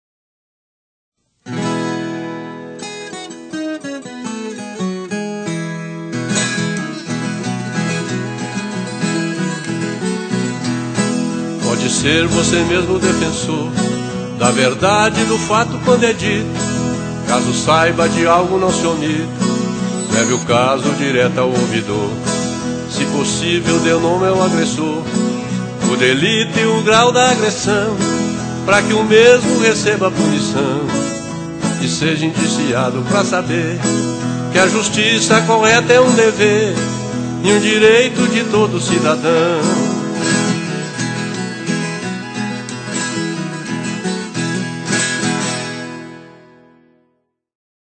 Faixa 10 - Spot Raiz 2